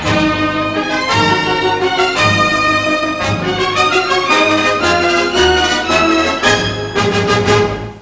Sigla finale